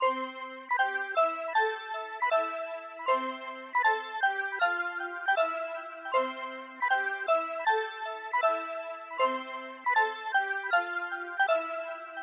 Tag: 157 bpm Hip Hop Loops Bells Loops 2.06 MB wav Key : Unknown